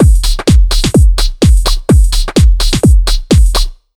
127BEAT6 3-L.wav